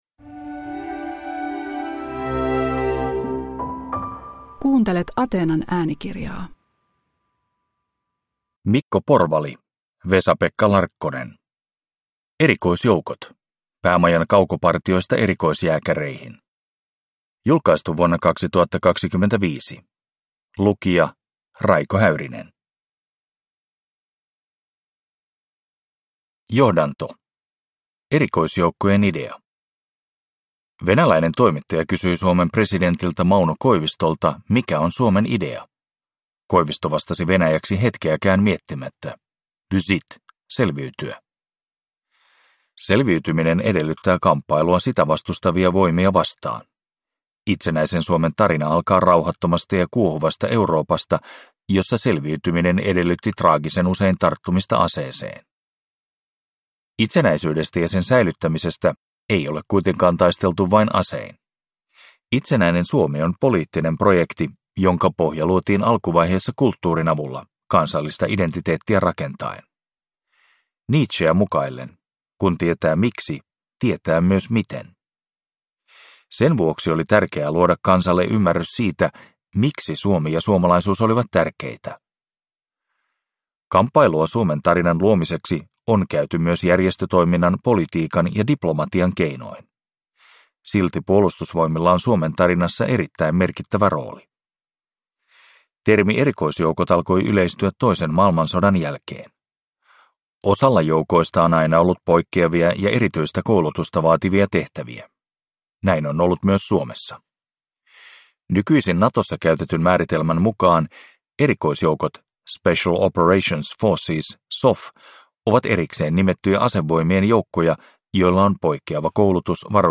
Erikoisjoukot – Ljudbok